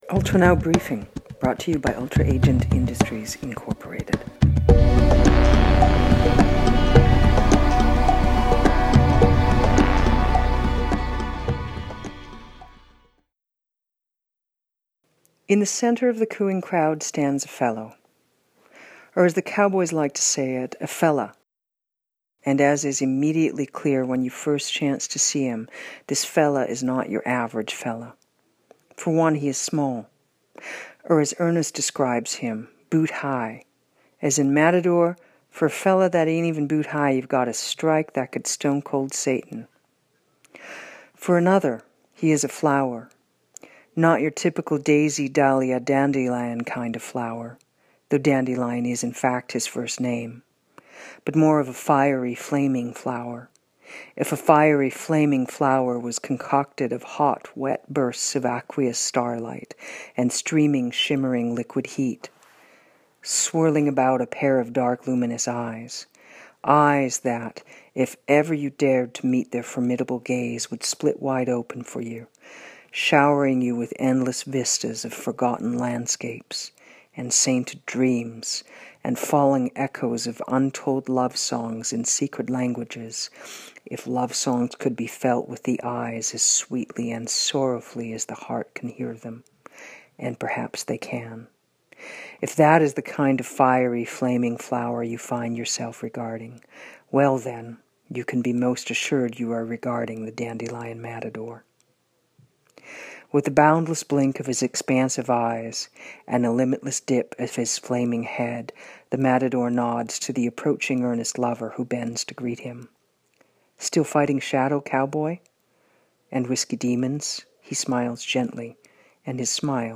Title credits music